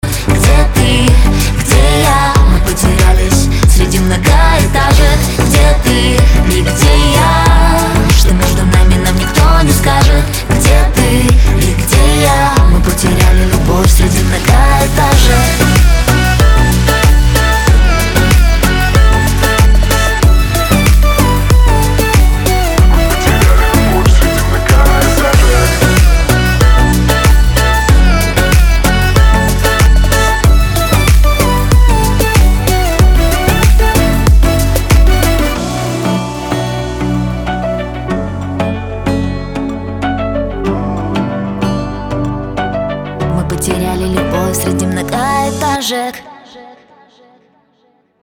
• Качество: 320, Stereo
поп
красивый женский голос